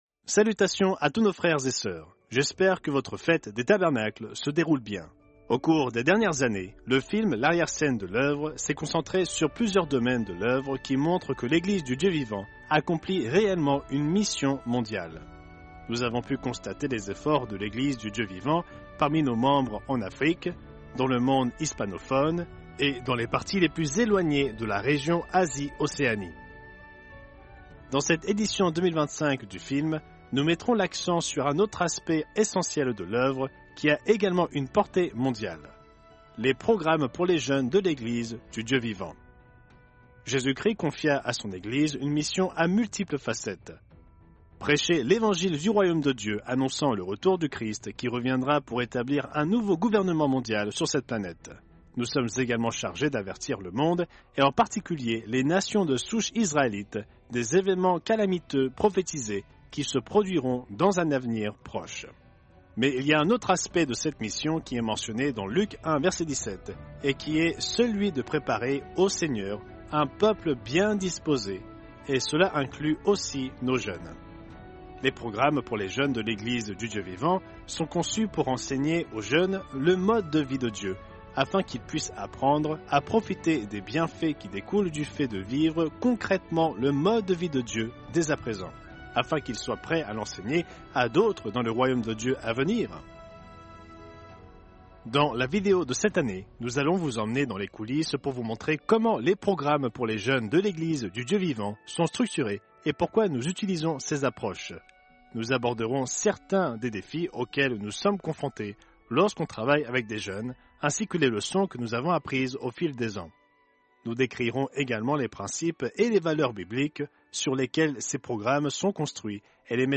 Fête des Tabernacles – 4e jour L’arrière-scène de l’Œuvre 2025